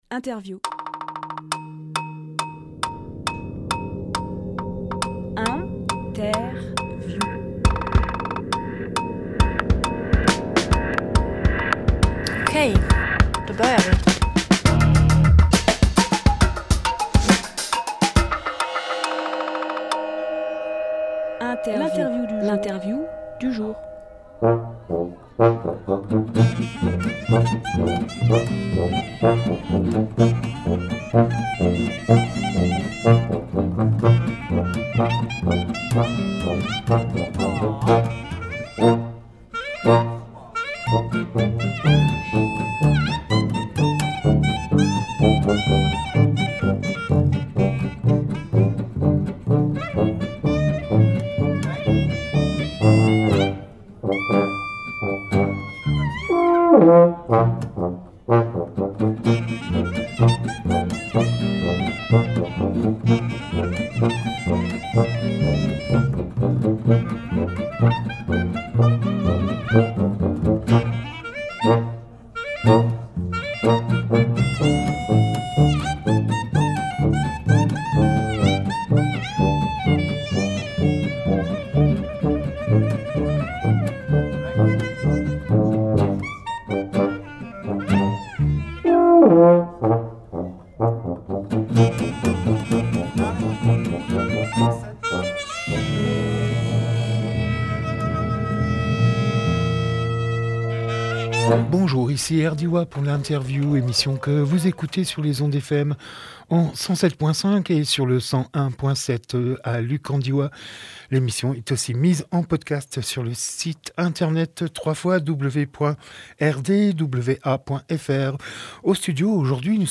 Emission - Interview Le Cabaret Cirque à Saint-Eloi (COMPLET) Publié le 10 novembre 2022 Partager sur…
08.11.22 Lieu : Studio RDWA Durée